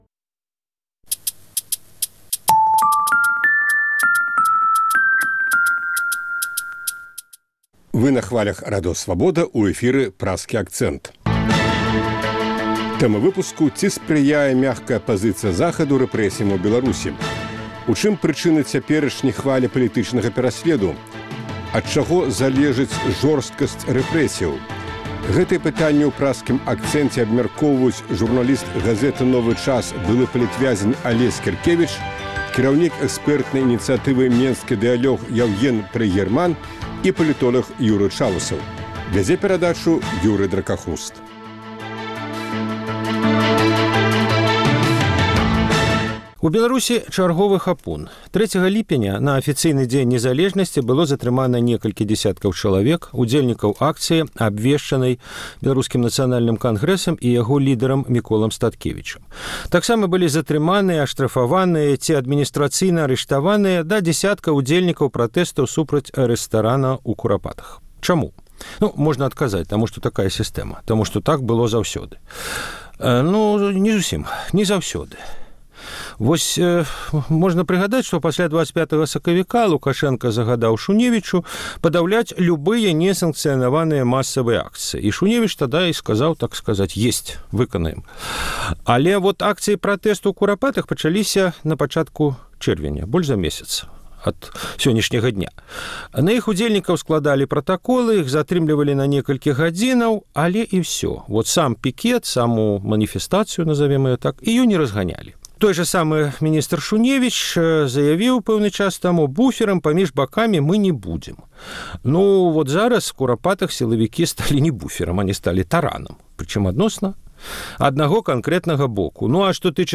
Ад чаго залежыць жорсткасьць рэпрэсіяў? Гэтыя пытаньні ў Праскім акцэнце абмяркоўваюць журналіст і пісьменьнік